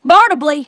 synthetic-wakewords
ovos-tts-plugin-deepponies_Applejack_en.wav